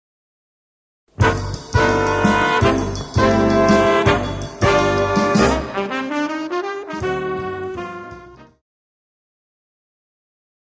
I kept it to medium swing, one quarter note equals 130.